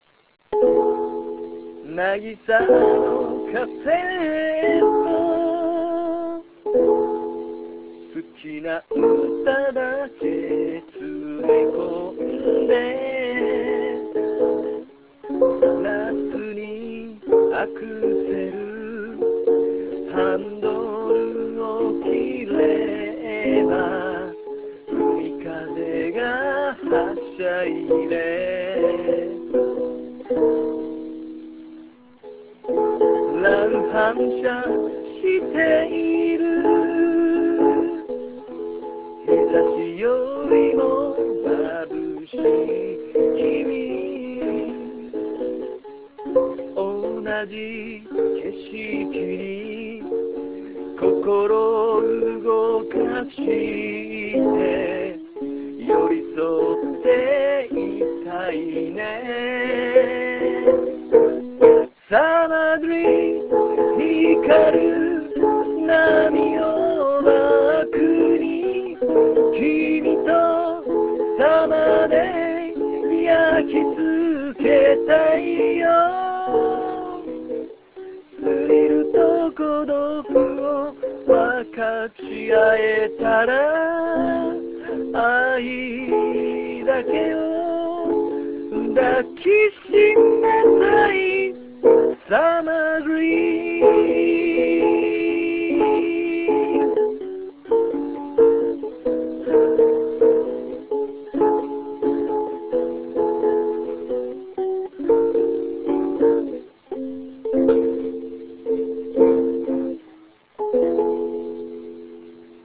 Ukulele de song